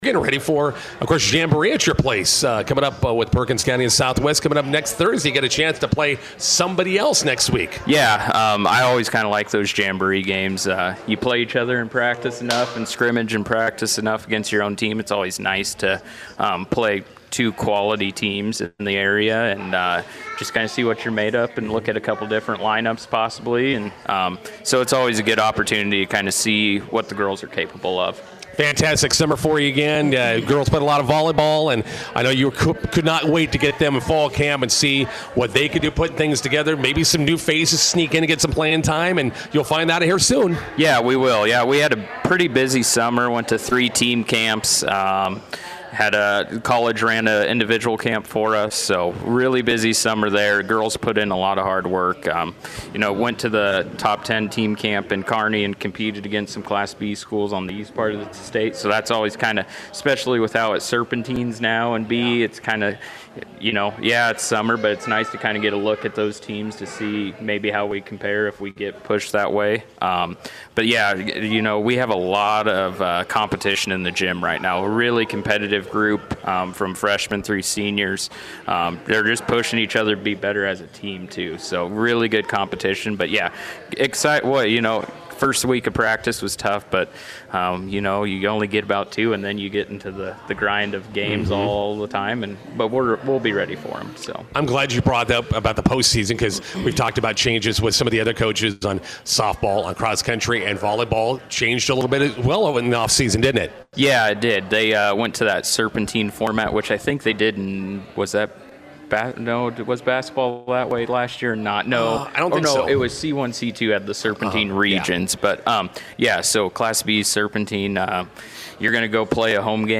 INTERVIEW: Bison volleyball with high expectations for the 2025 fall season. Host Southwest and Perkins County in NSAA jamboree matches.